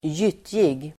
Ladda ner uttalet
Uttal: [²j'yt:jig]
gyttjig.mp3